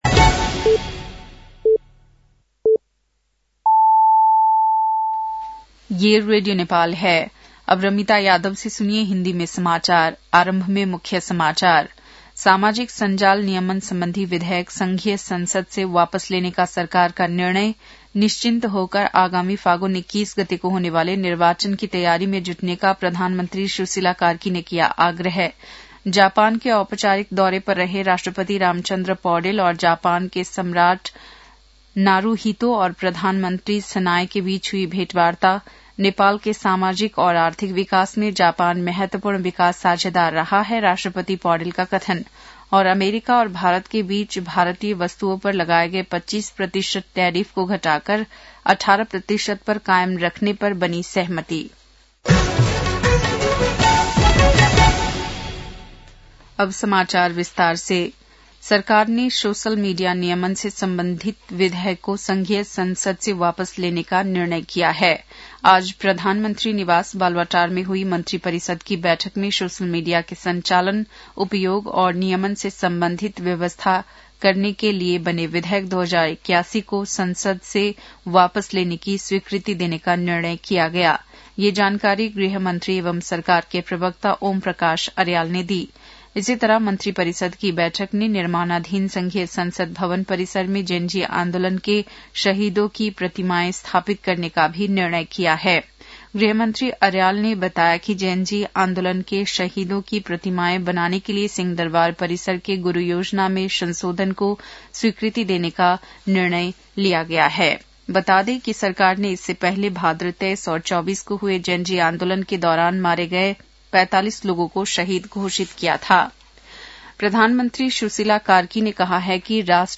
बेलुकी १० बजेको हिन्दी समाचार : २० माघ , २०८२